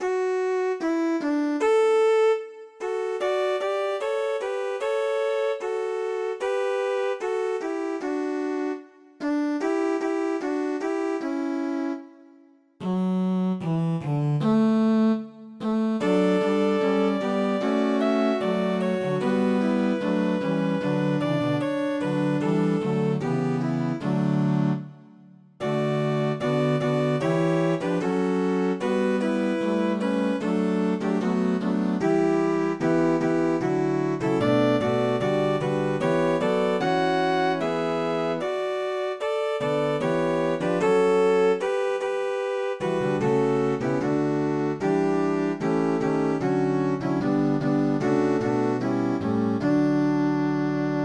4 bè